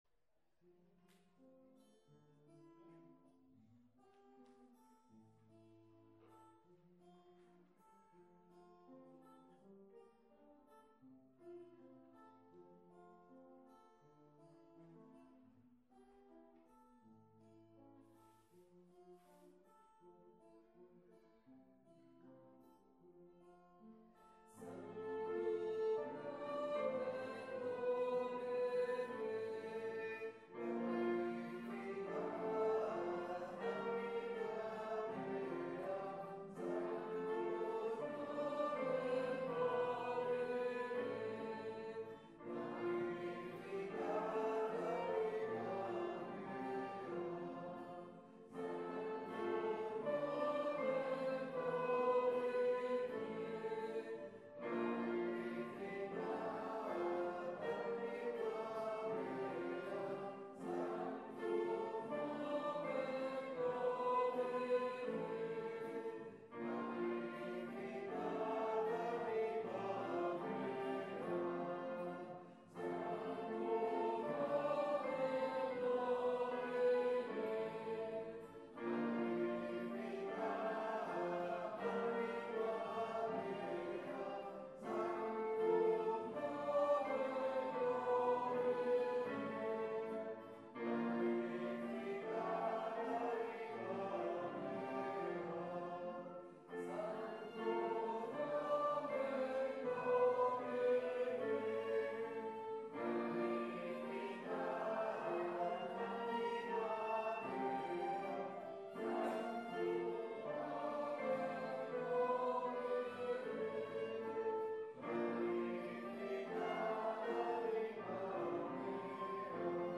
Pregària de Taizé
Parròquia de Maria Auxiliadora - Diumenge 27 de setembre de 2015
Vàrem cantar...